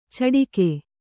♪ caḍike